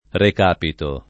rek#pito] (raro ricapito [rik#pito]) s. m. — es. con acc. scr.: per quest’altro medico suo amico, che ha recàpito alla prossima farmacia [